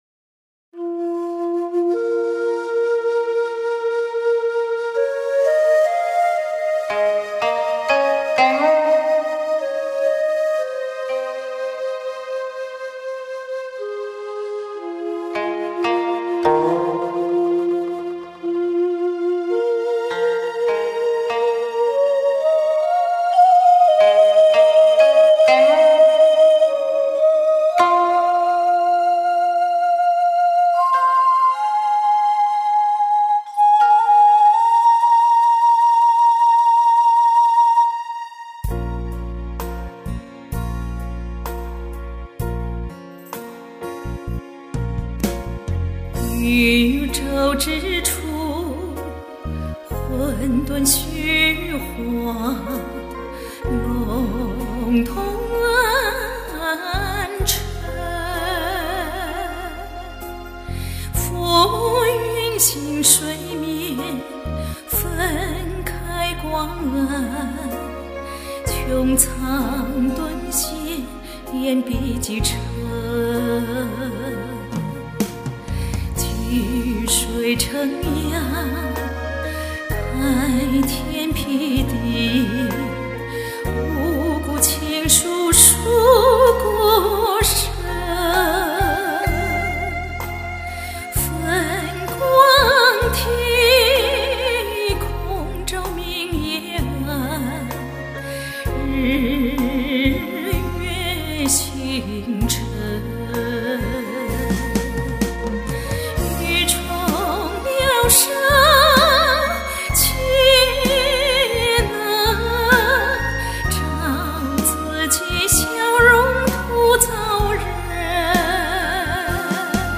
【圣歌大赛】|《沁园春·创世纪》